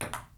Index of /90_sSampleCDs/Roland L-CD701/PRC_FX Perc 1/PRC_Ping Pong